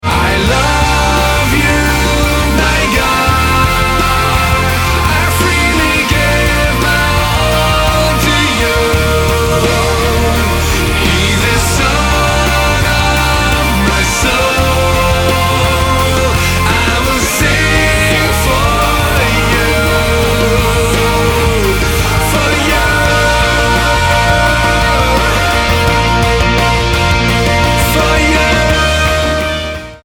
Worship Album